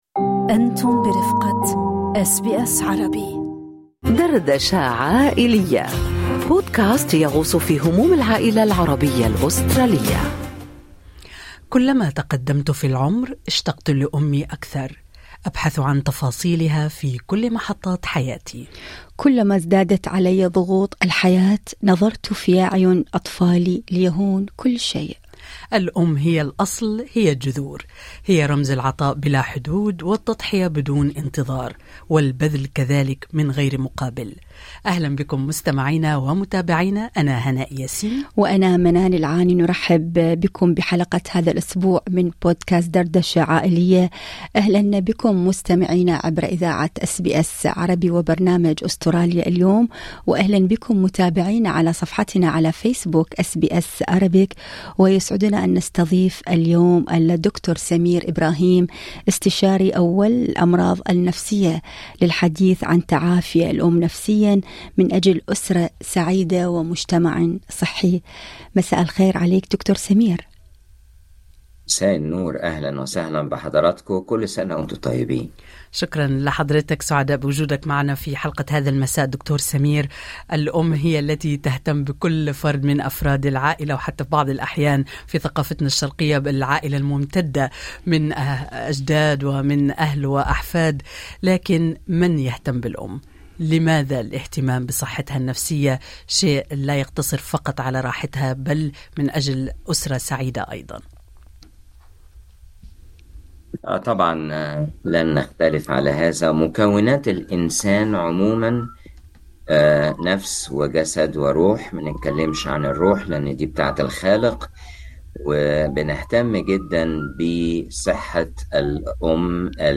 كيف تتعافى الام نفسيا من أجل عائلة سعيدة ومجتمع صحي؟ استشاري نفسي يجيب